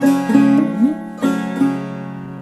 SAROD1    -R.wav